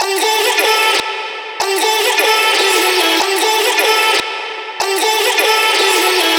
VR_vox_hit_intothedark_high_E.wav